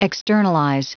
Prononciation du mot externalize en anglais (fichier audio)
externalize.wav